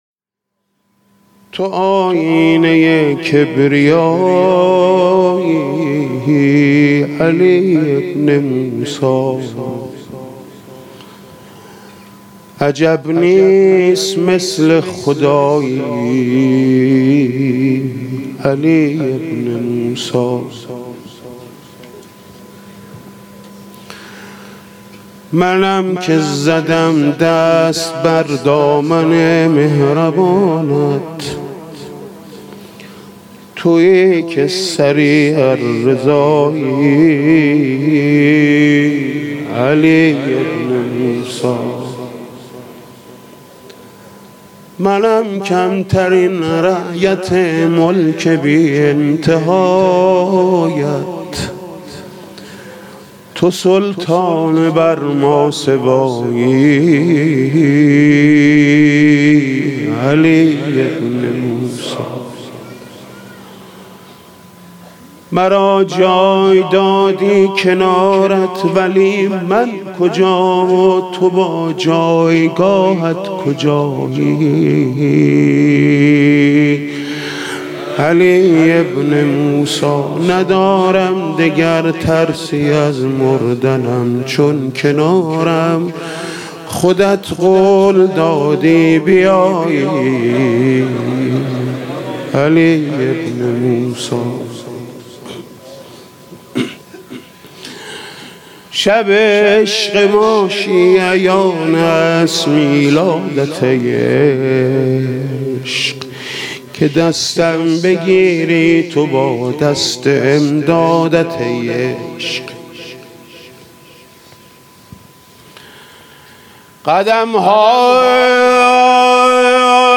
«میلاد امام رضا 1395» مدح: تو آیینه کبریایی علی ابن موسی